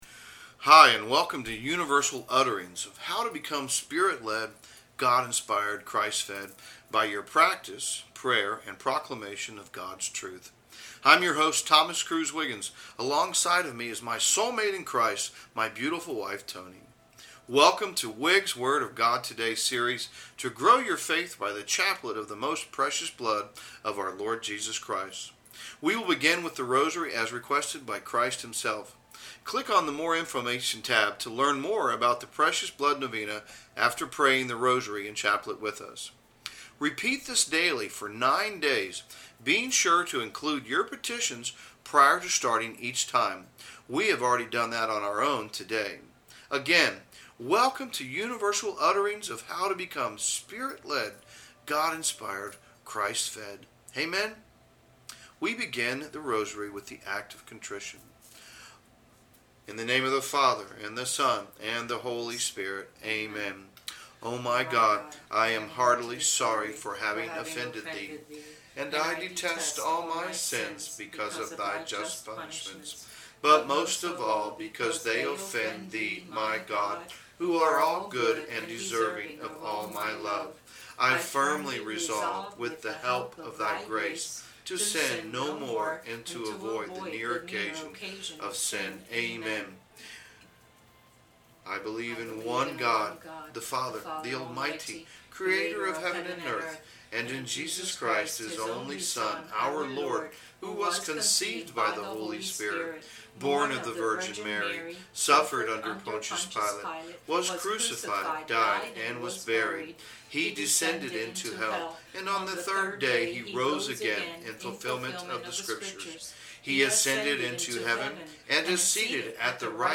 Rosary for Precious Blood Novena
Rosary-Lead-for-Precious-Blood.mp3